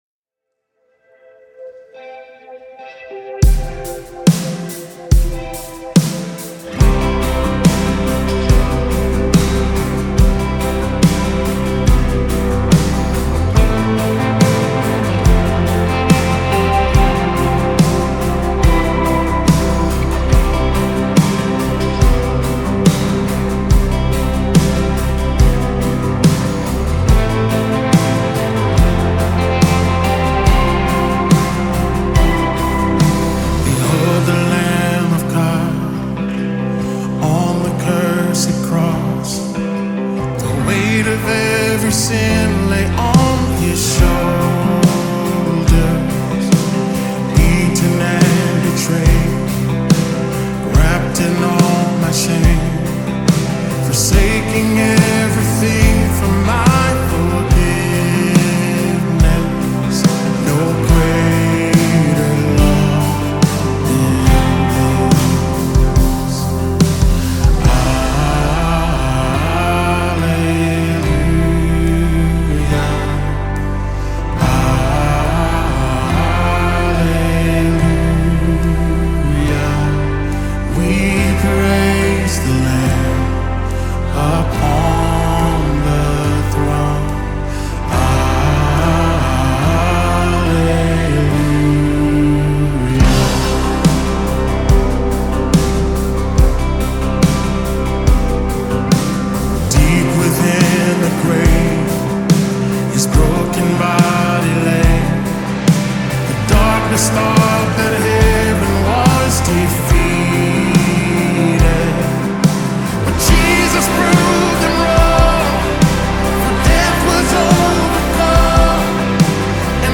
Trending Gospel Songs